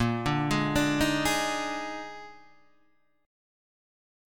A# 11th